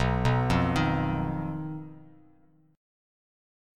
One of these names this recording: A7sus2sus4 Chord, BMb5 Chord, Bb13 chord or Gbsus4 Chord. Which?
BMb5 Chord